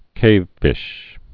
(kāvfish)